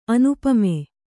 ♪ anupame